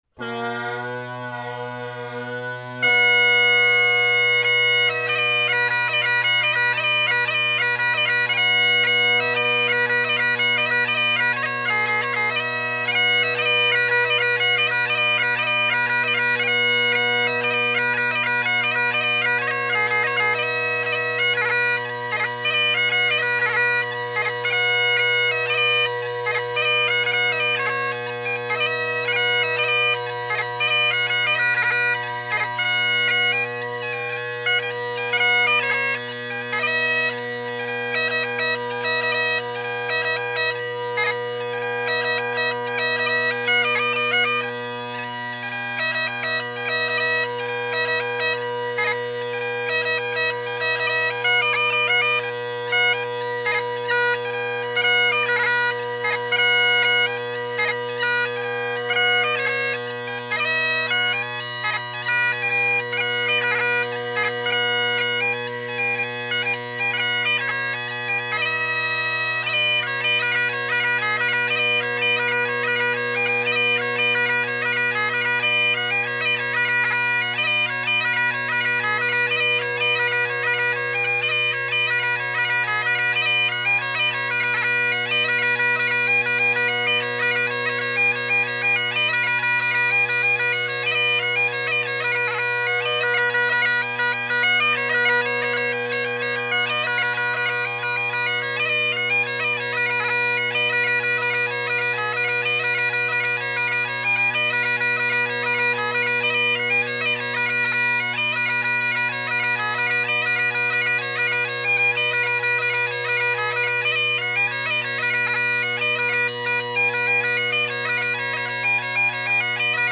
Great Highland Bagpipe Solo
The first track was meant to exhibit the very awesome high A, except it went sharp about 20 seconds in so you’ll have to catch a glimpse from the latter two recordings, Song for Winter being a good one, F too, and B, good chanter.